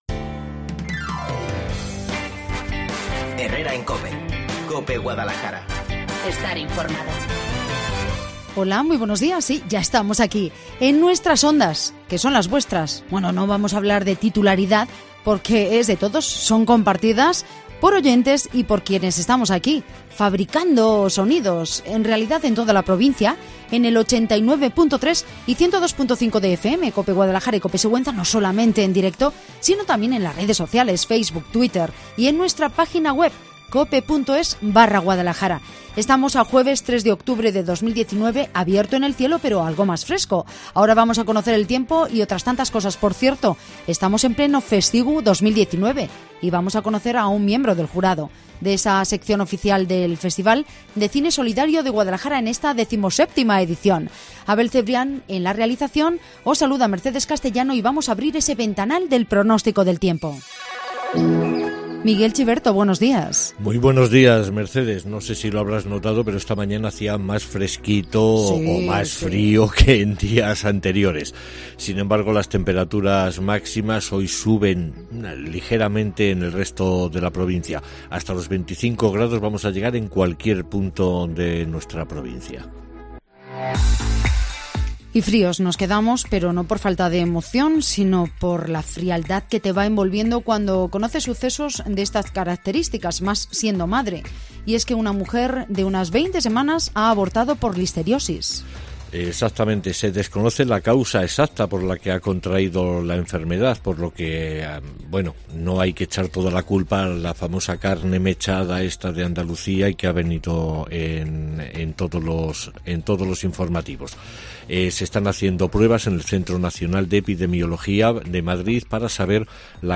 AUDIO: Entre 12:50 y 13:00, charlamos con el actor Unax Ugalde, miembre del jurado del FESCIGU 2019